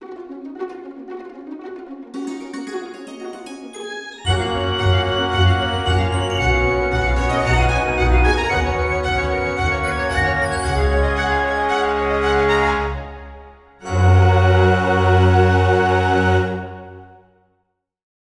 This music portrays a family culture of entrepreneurial drive. Running to catch up, finding something new, running beyond, and ultimately returning in peace we are